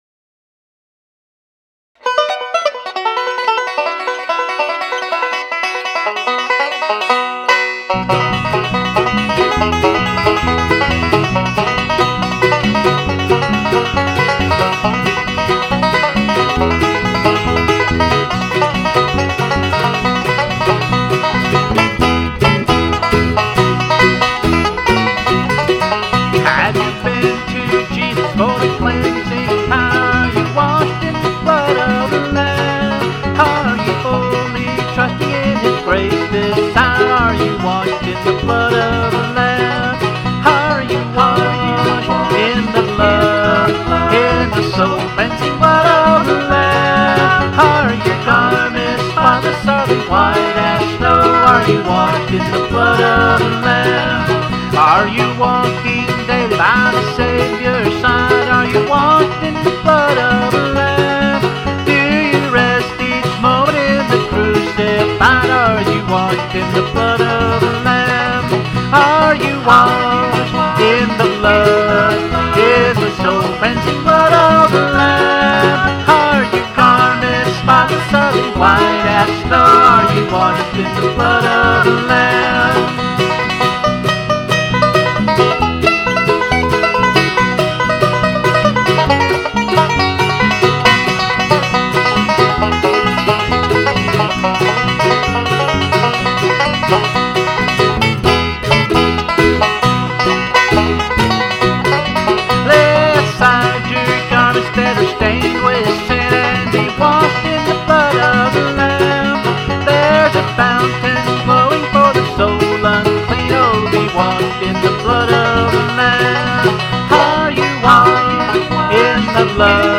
BLUEGRASS